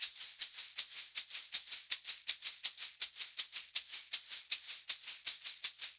80 SHAK 06.wav